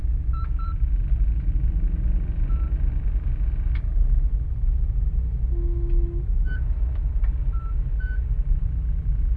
loading_hum.wav